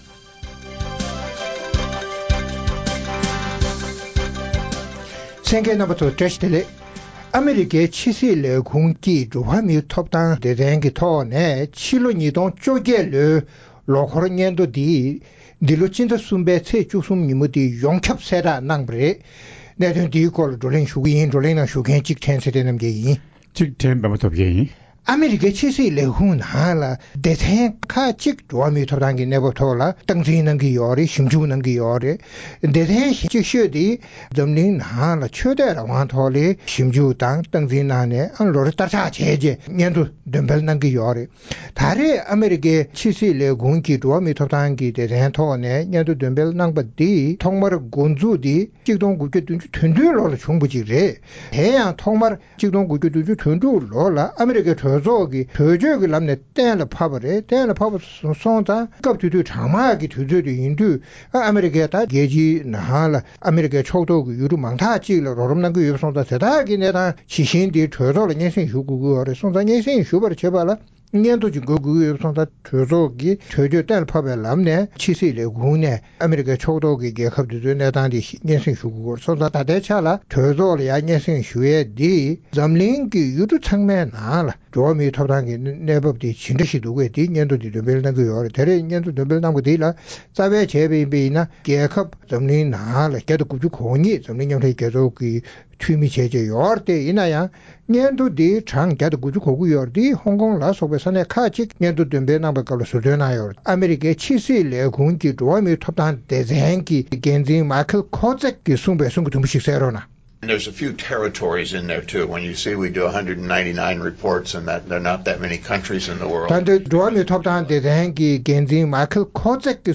བོད་ནང་གི་འགྲོ་བ་མིའི་ཐོབ་ཐང་གི་གནས་སྟངས་ཐད་བགྲོ་གླེང༌།
༄༅། །ཨ་མི་རི་ཀའི་ཕྱི་སྲིད་ལས་ཁུངས་ཀྱིས་ཕྱི་ཟླ་༣་པའི་ཚེས་༡༣་ཉིན། ཕྱི་ལོ་༢༠༡༨་ལོའི་འཛམ་གླིང་ནང་འགྲོ་བ་མིའི་ཐོབ་ཐང་གི་ལོ་འཁོར་སྙན་ཐོ་འདོན་སྤེལ་གནང་བ་དང་འབྲེལ། བོད་ནང་གི་འགྲོ་བ་མིའི་ཐོབ་ཐང་ལ་སོགས་པར་དམ་དྲག་བྱེད་བཞིན་པའི་ཞིབ་ཕྲའི་སྙན་ཐོའི་གནད་དོན་སྐོར་རྩོམ་སྒྲིག་འགན་འཛིན་རྣམ་པས་བགྲོ་གླེང་གནང་བ་ཞིག་གསན་རོགས་གནང་།།